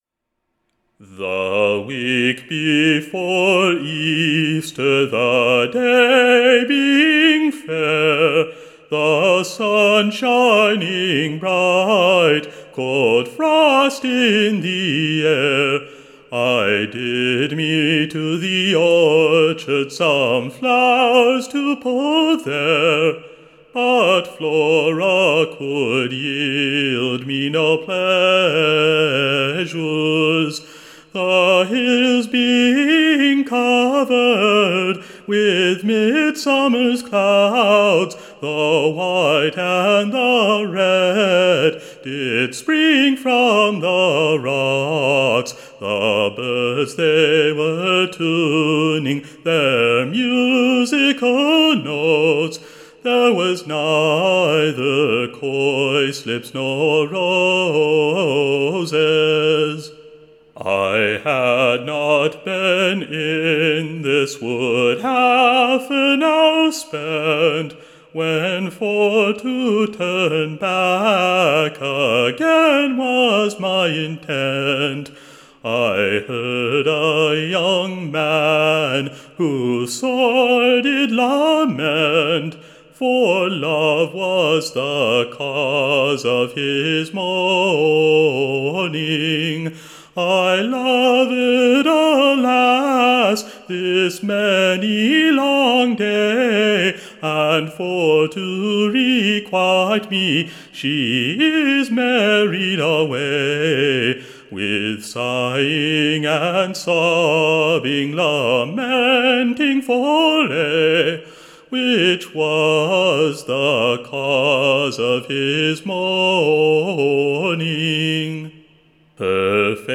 Recording Information Ballad Title LOVE is the Cause / of my Mourning.
Tune Imprint Sung with its own proper Tune. Standard Tune Title Love is the Cause of My Mourning Media Listen 00 : 00 | 11 : 47 Download r3.672_Love_is_the_Cause.mp3 (Right click, Save As)